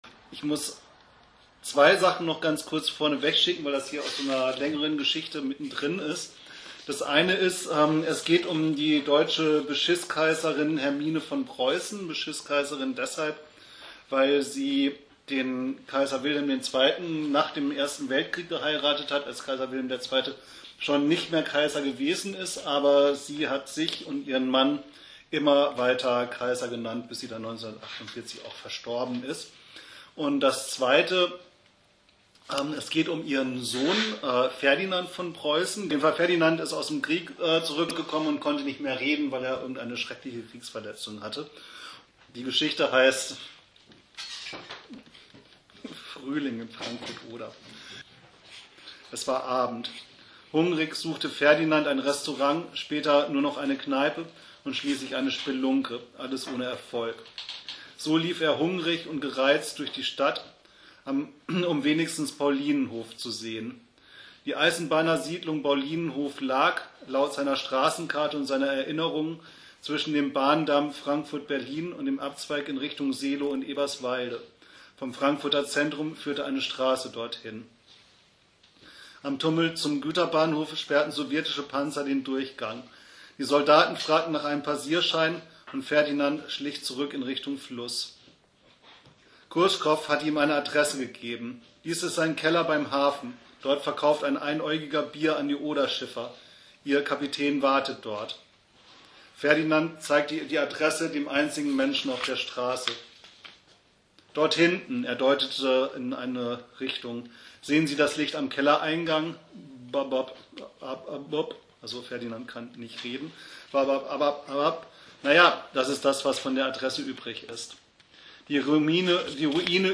ON STAGE: Frühling in Frankfurt